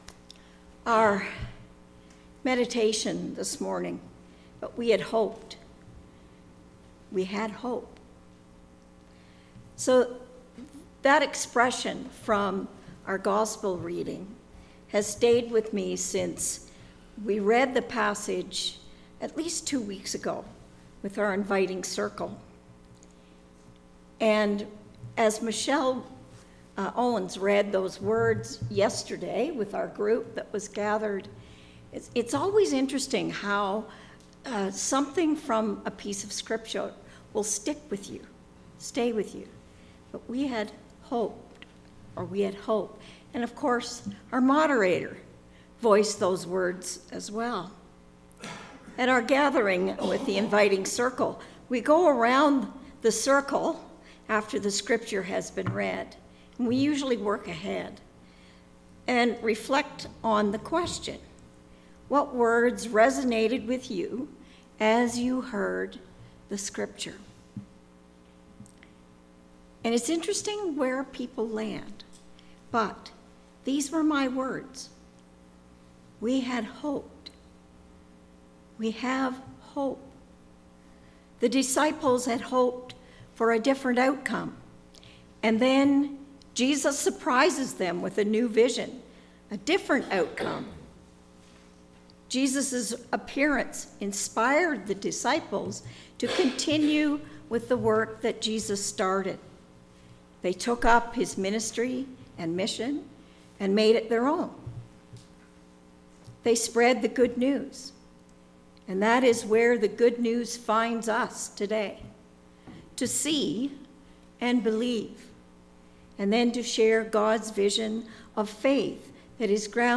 This is a shortened version of our in-person  Sunday Service.
Hymn: The Lord is My Shepard by Allen Pote
pianist